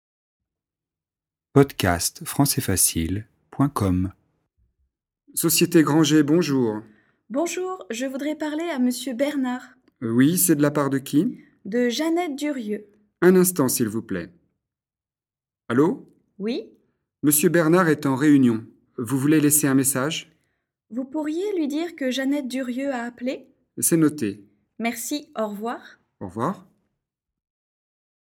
Dialogue FLE et exercice de compréhension, niveau intermédiaire (A2) sur un thème de la vie quotidienne "répondre au téléphone".